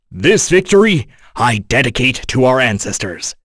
Dakaris-Vox_Victory.wav